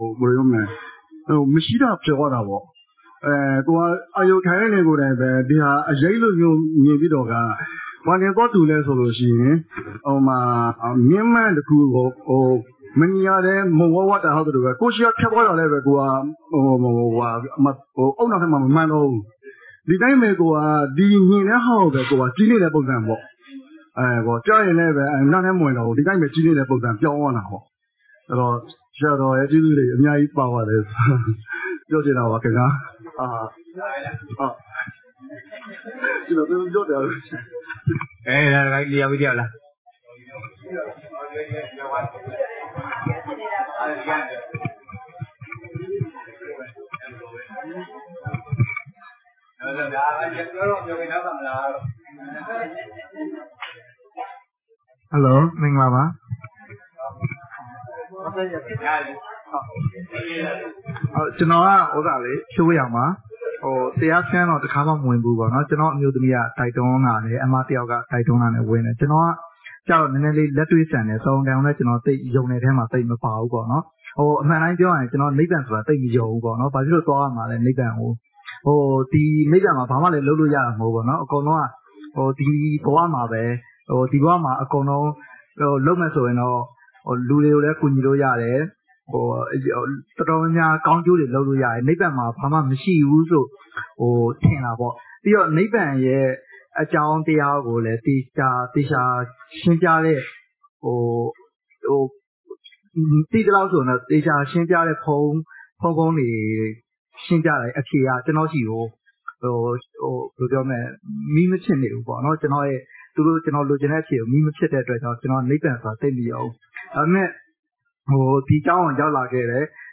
Feb26_2023_၁၄ကြိမ်နှစ်ရက်စခန်း Day2 Part5_တရားအတွေ့အကြုံဆွေးနွေး